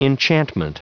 Prononciation du mot enchantment en anglais (fichier audio)
Prononciation du mot : enchantment